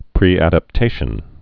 (prēăd-ăp-tāshən, -əp-)